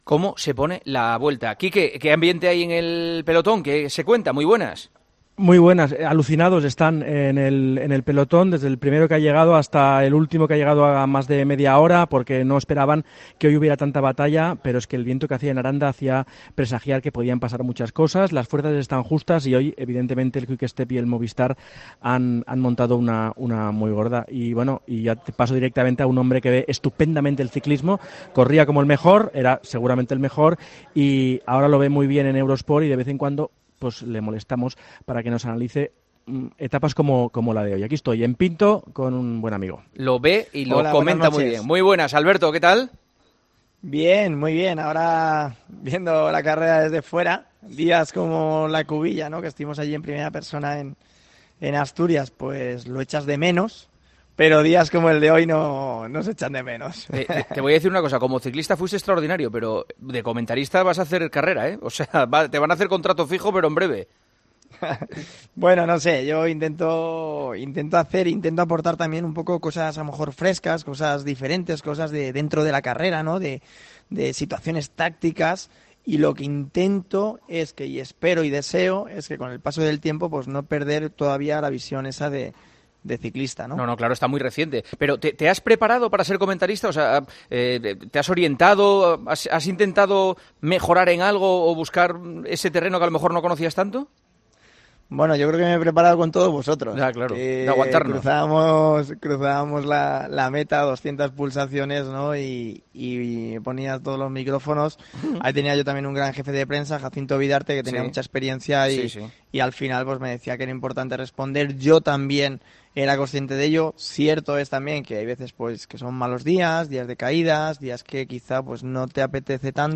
Alberto Contador ha pasado por los micrófonos de El Partidazo de COPE para analizar cómo ha transcurrido hasta el momento La Vuelta Ciclista a España y lo que ha dado de sí la etapa del miércoles.